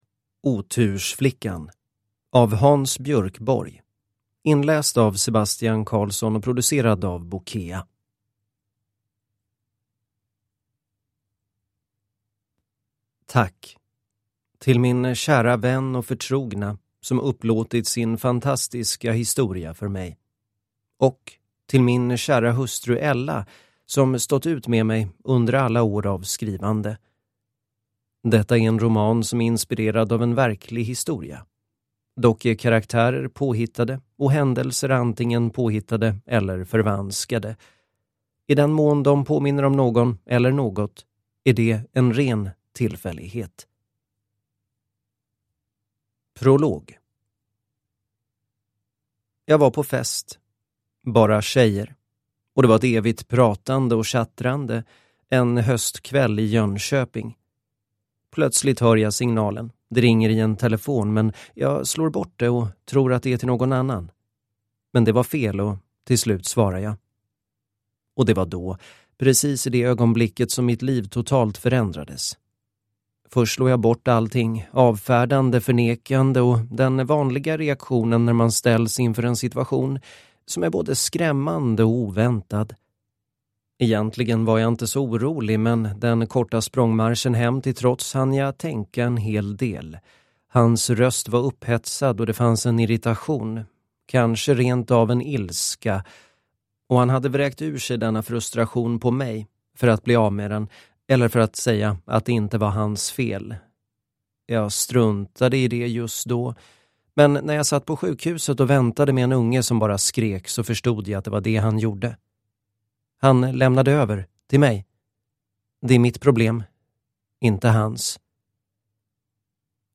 Otursflickan – Ljudbok